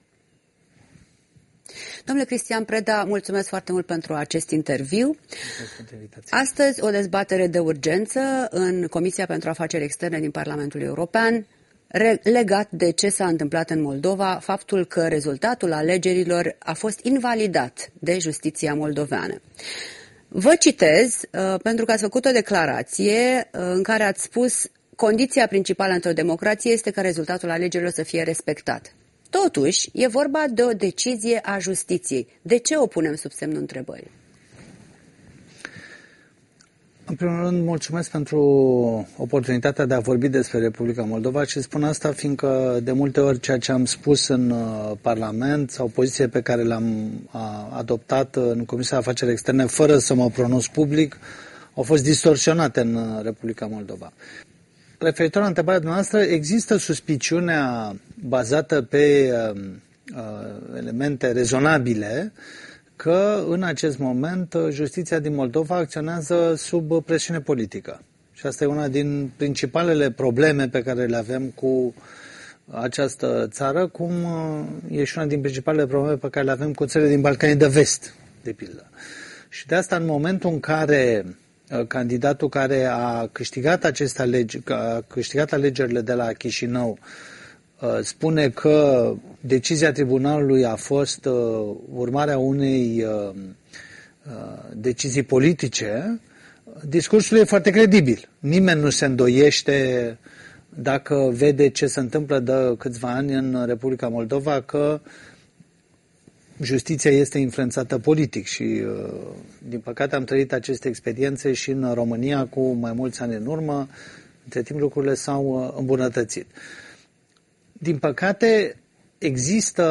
Interviu cu eurodeputatul român din grupul popular-europenilor.